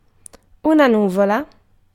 Ääntäminen
Tuntematon aksentti: IPA: /moːln/